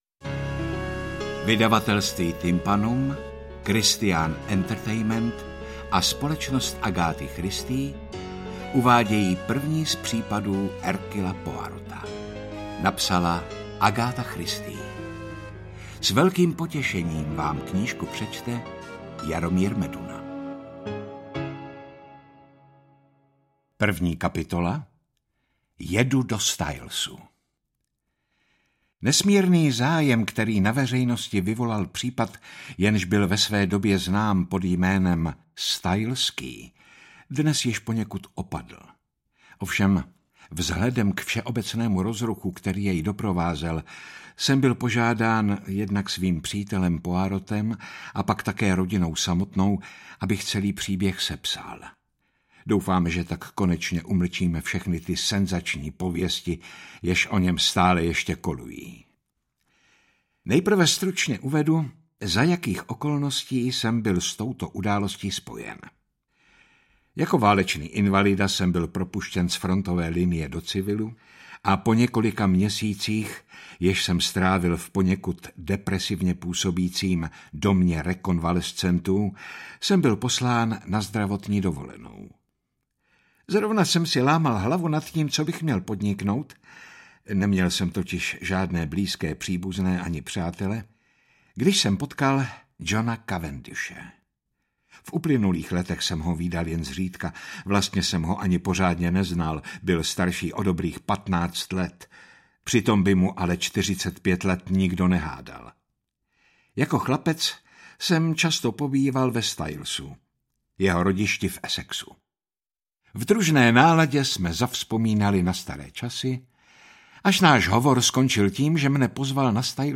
Interpret:  Jaromír Meduna
Ten kdo zná a miluje Agathu Christie si pochutná na známém díly v podání pana Meduny, který nadaboval Hercula Poirota ve filmovém a televizním zpracování.